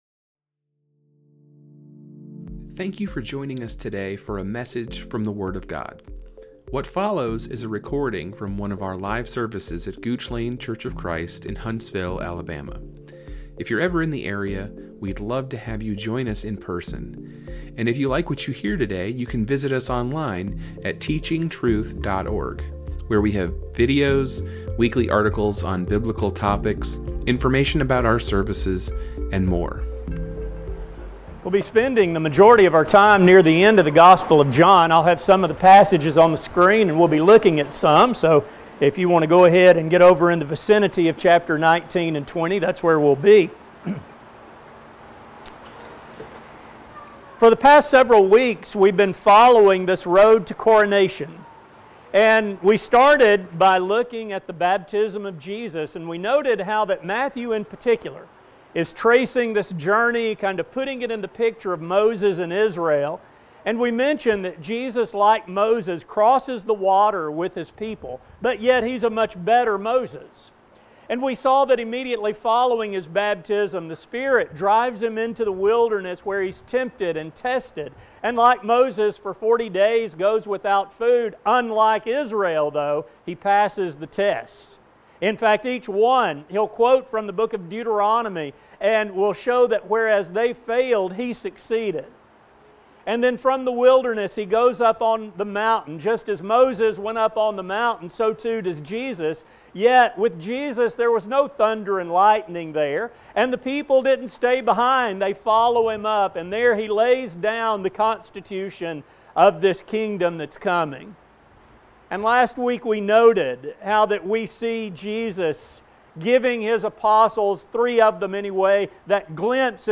This sermon will examine three who testified about Him, focusing on how their words show the fulfillment of God’s plan from the beginning. A sermon